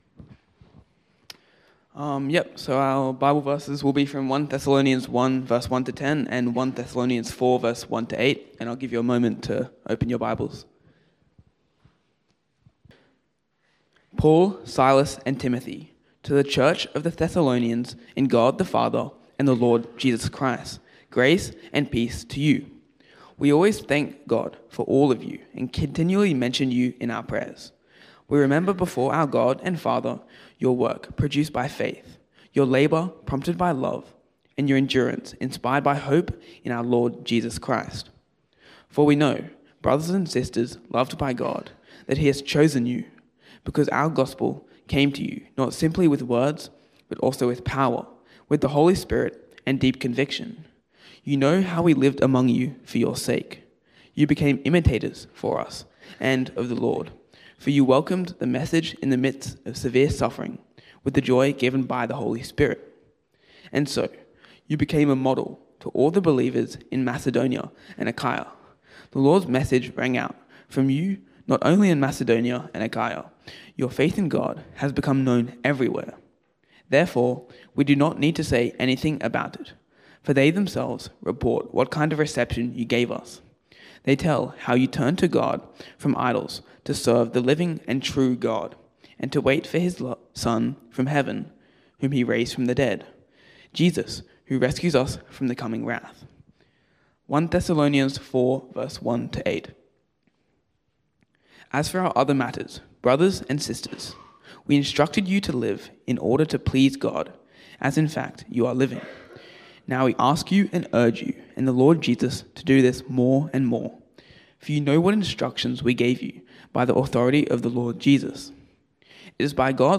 PennoBaps Sermons
Talks from Pennant Hills Baptist